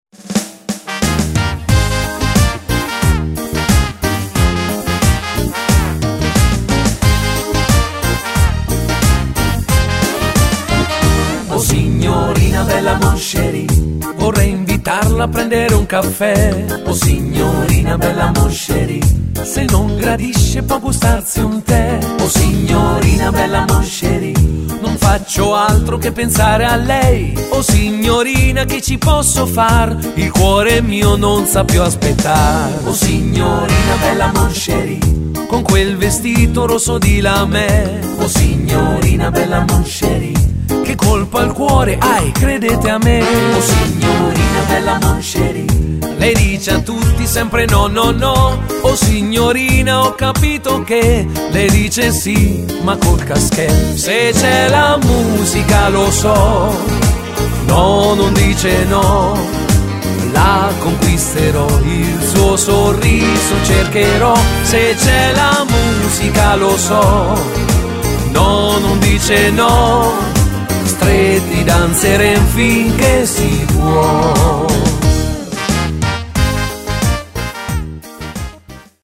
Bajon
Uomo